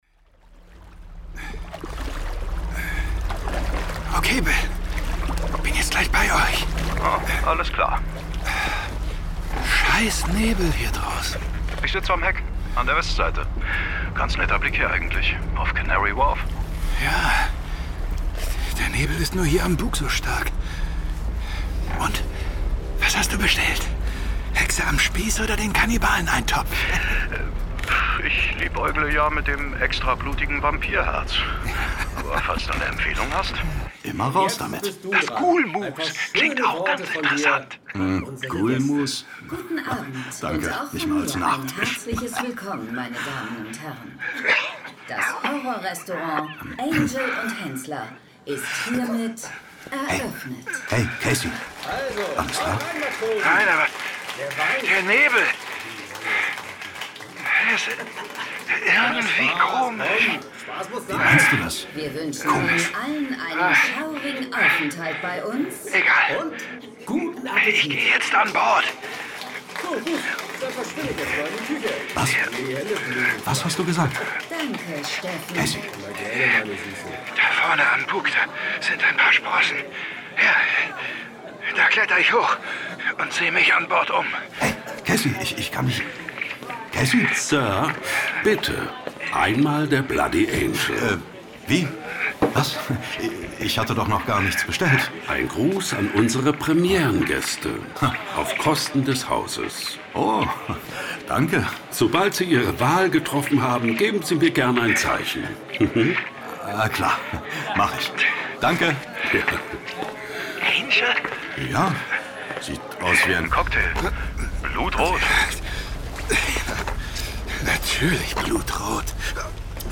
John Sinclair - Das Horror-Restaurant Sonderedition 17. Hörspiel.